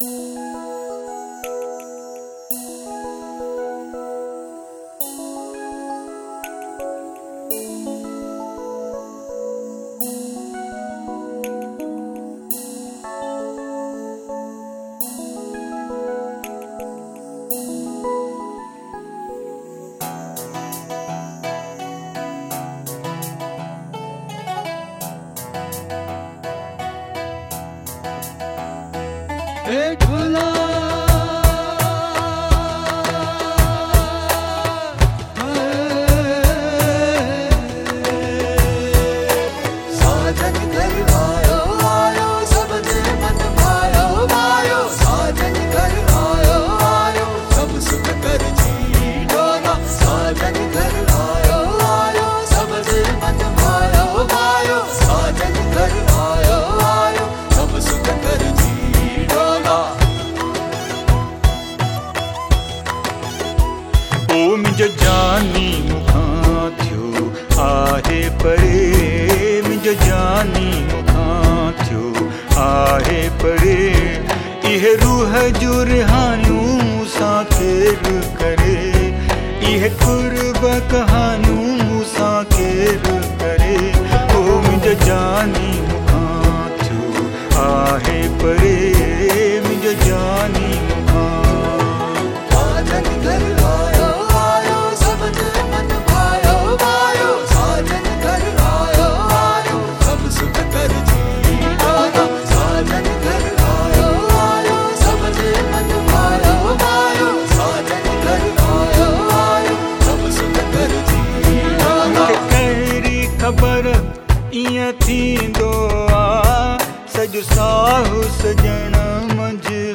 Sufi Kalams and Jhulelal Bhajans